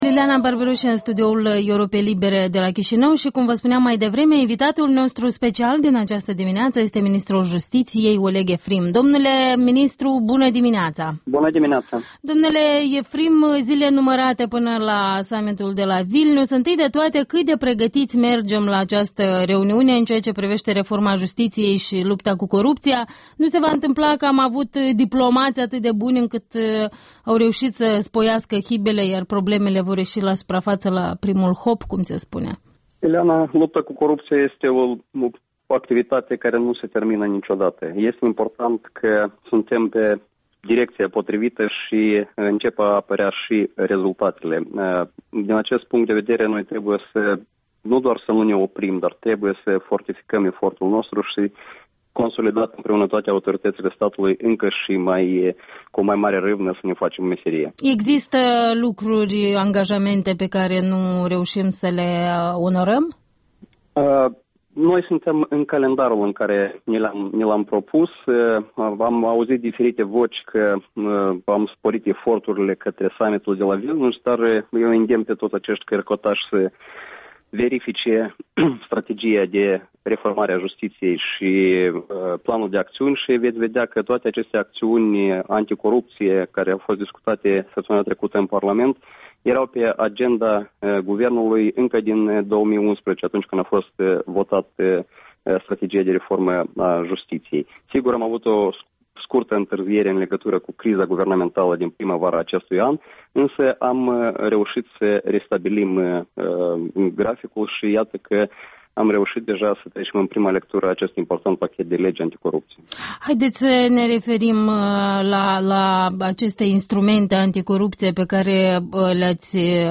Interviul dimineții: cu Oleg Efrim, ministrul justiției